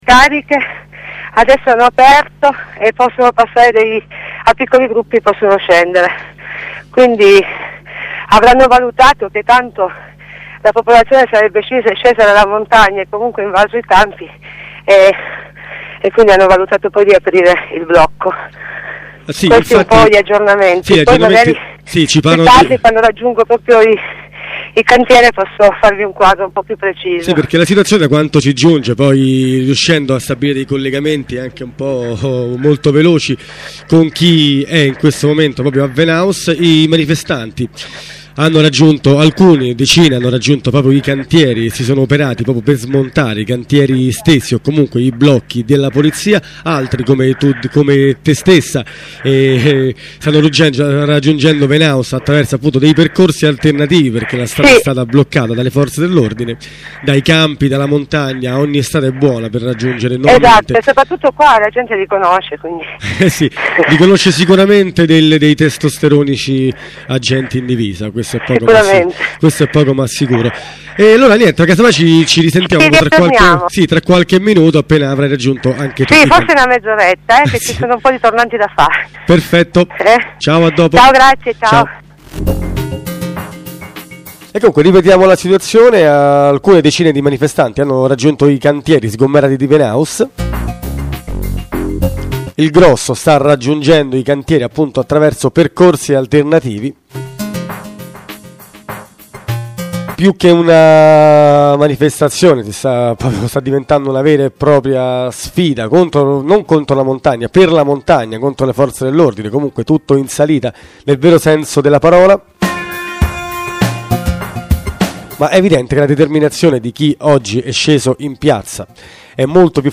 collegamento delle 12.30